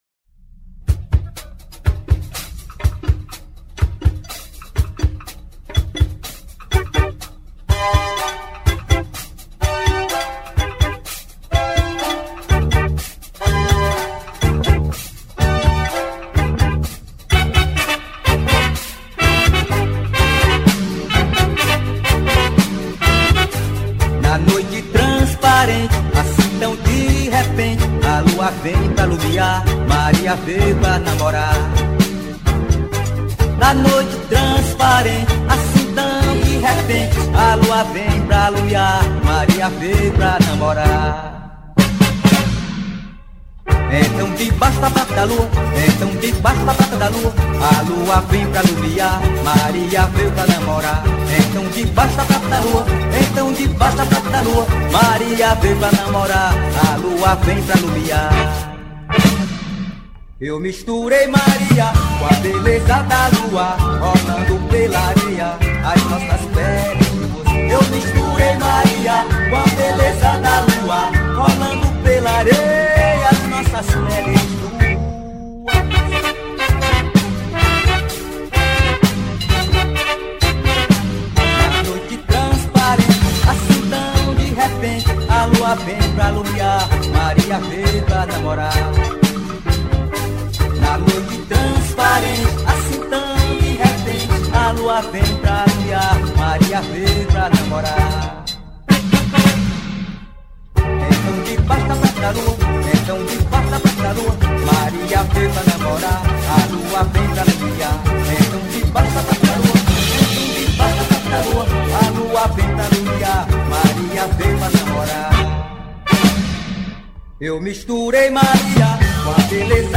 2639   02:33:00   Faixa: 12    Rock Nacional